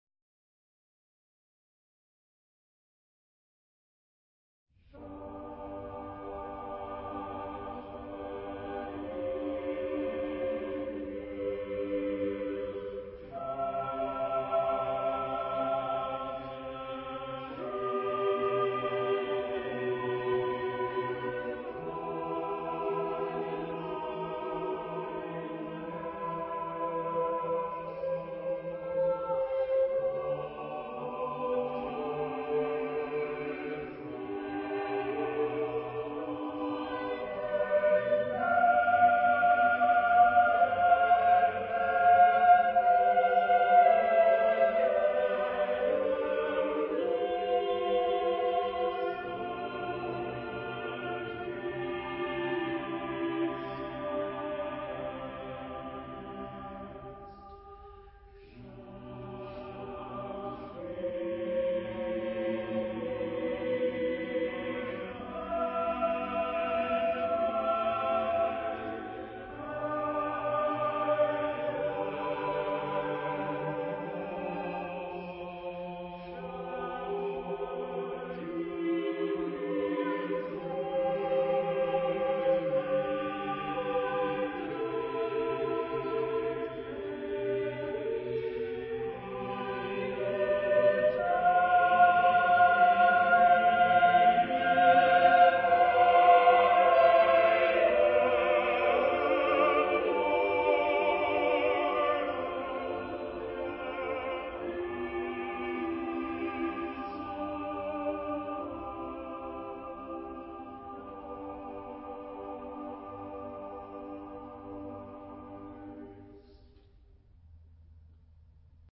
Genre-Style-Forme : Sacré ; Romantique ; Motet en forme de choral
Caractère de la pièce : solennel
Type de choeur : SATBB  (5 voix mixtes )
Tonalité : do majeur ; sol majeur
interprété par The Schütz Choir of London dirigé par Roger Norrington